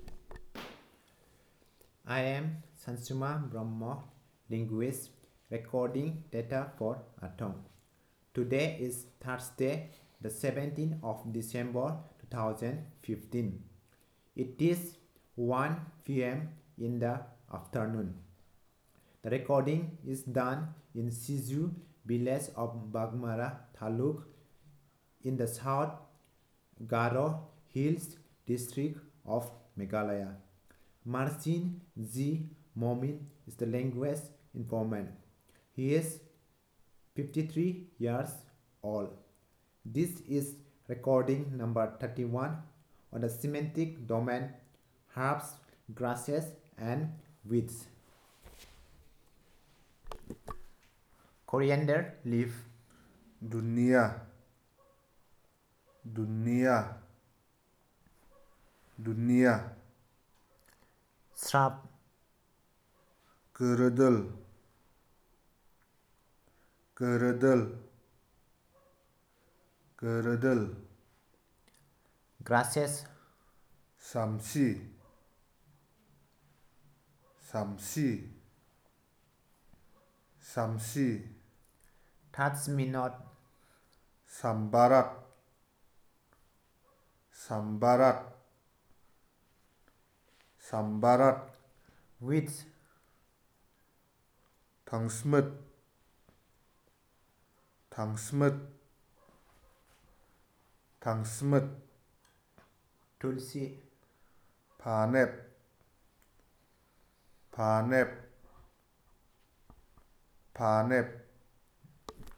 Elicitation of words about herbs, grasses, shrubs and weeds